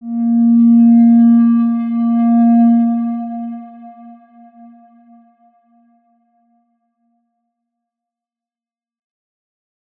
X_Windwistle-A#2-mf.wav